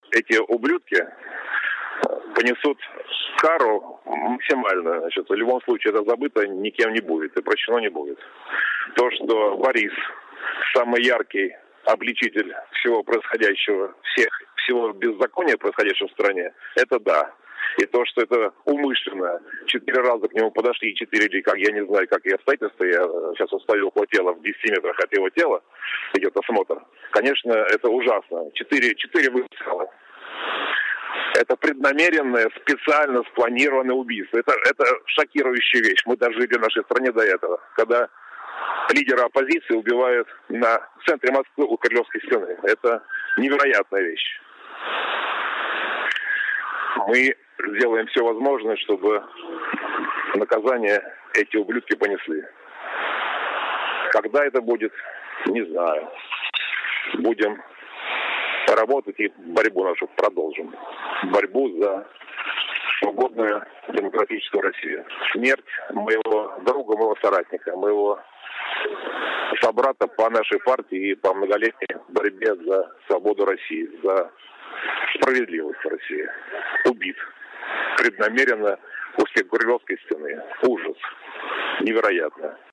Комментарий Михаила Касьянова